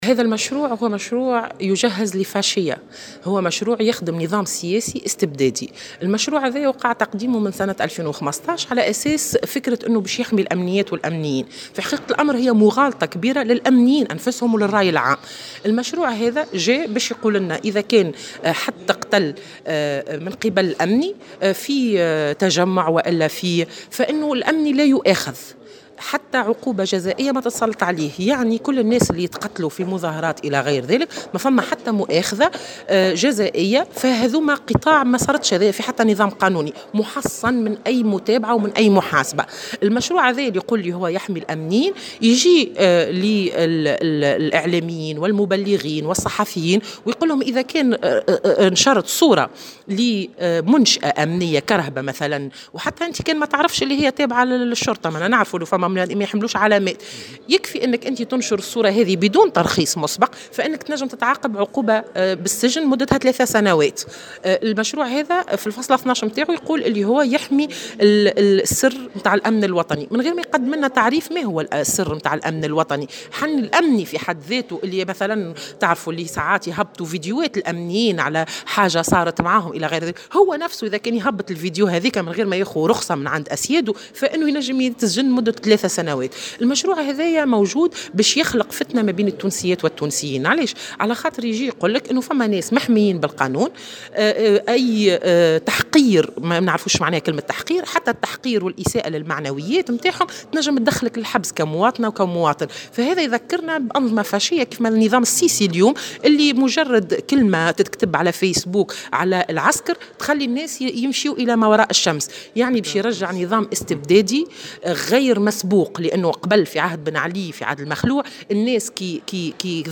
تصريح لمراسل الجوهرة "اف ام"